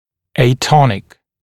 [eɪ’tɔnɪk][эй’тоник]атонический, вялый, слабый